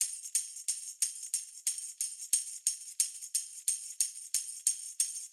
Index of /musicradar/sampled-funk-soul-samples/90bpm/Beats
SSF_TambProc1_90-03.wav